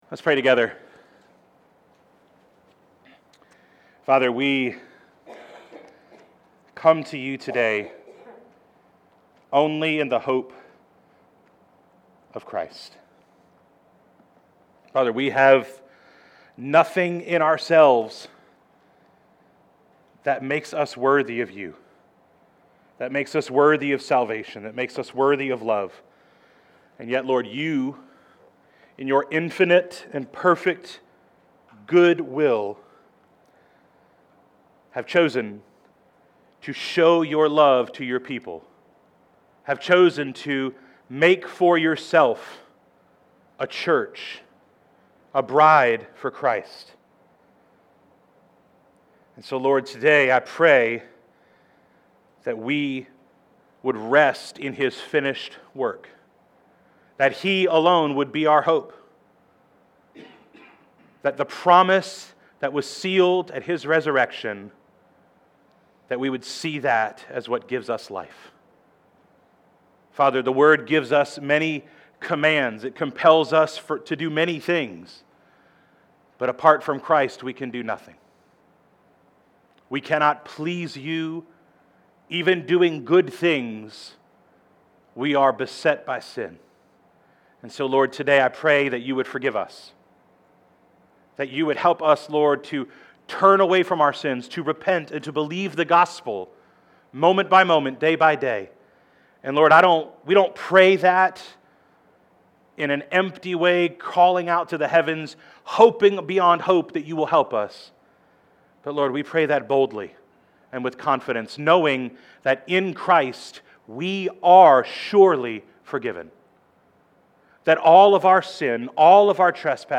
Sermons | Evans Creek Baptist Church